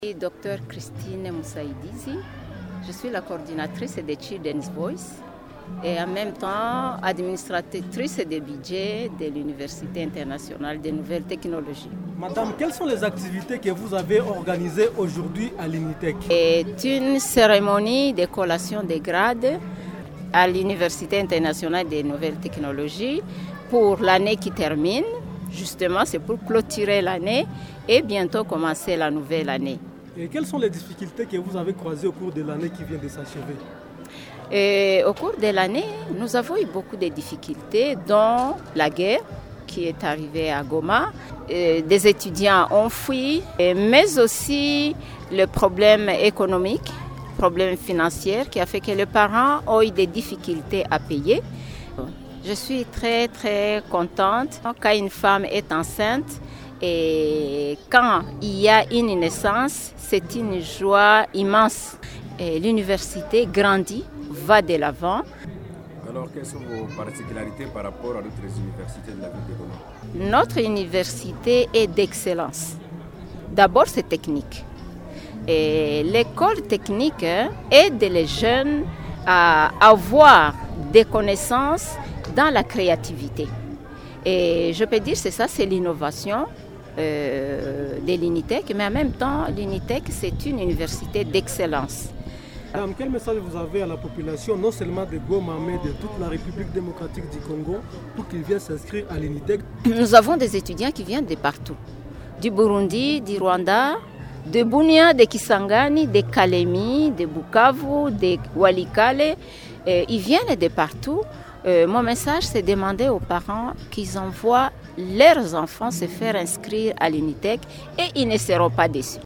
Ce vendredi 10 septembre 2025 -L’université Internationales des nouvelles technologies UNITECH Goma a procédé à la clôture officielle de l’année académique 2024-2025.